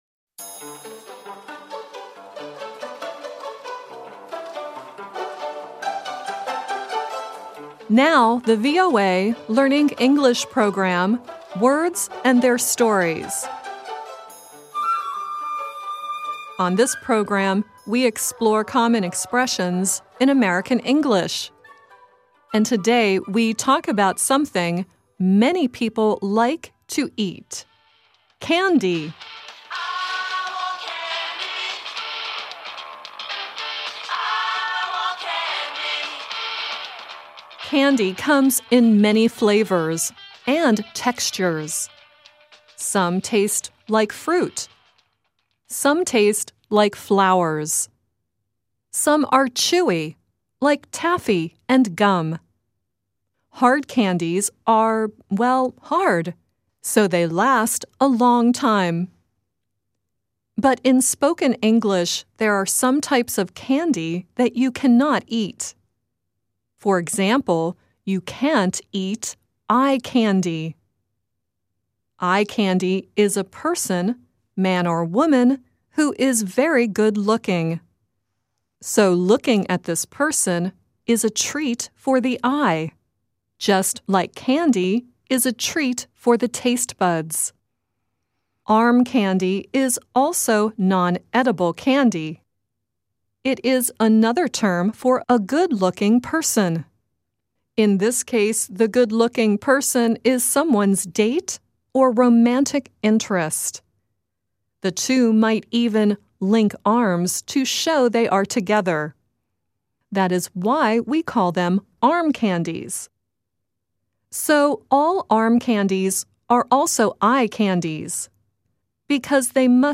Kalau bisa diusahakan bacanya mirip dengan native speaker dibawah ini, oke..
Earlier in the story, you heard the pop group Bow Wow Wow singing “I Want Candy.” The song at the end is Sammy Davis Jr. singing “The Candy Man,” which was first heard in the famous 1971 movie “Willie Wonka & the Chocolate Factory.”